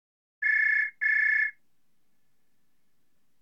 calling.ogg